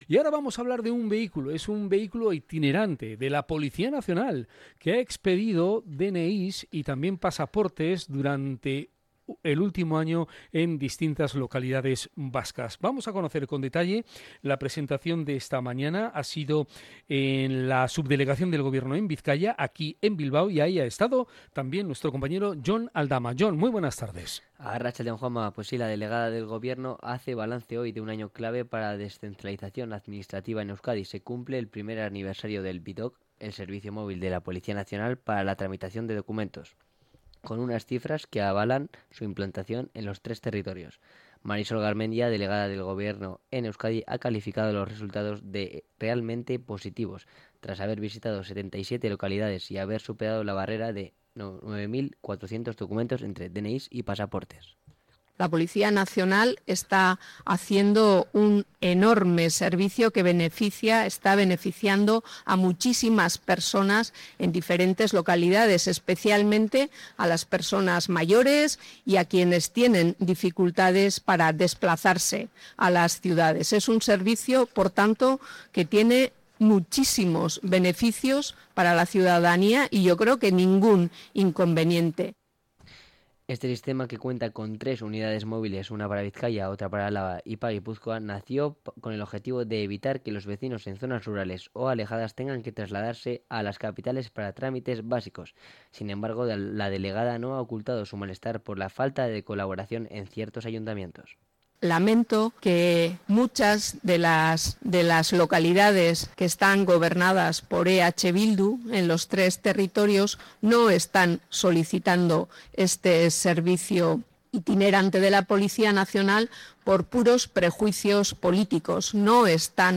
cronica_vidoc.mp3